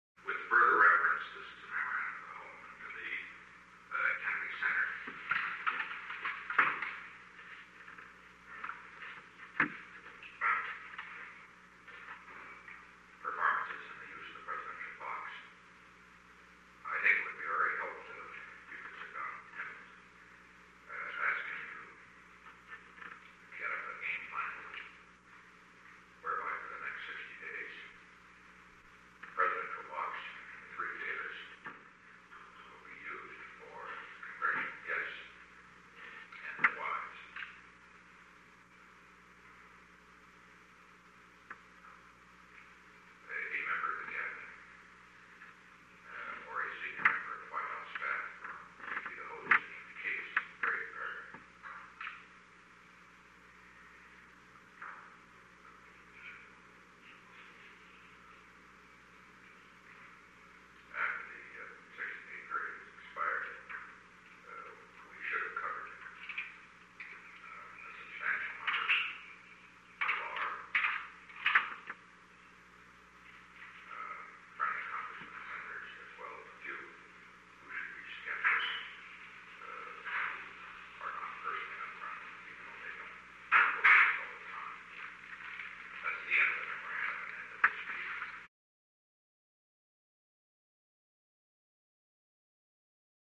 Secret White House Tapes
Location: Oval Office
The President dictated a memorandum to H. R. (“Bob”) Haldeman.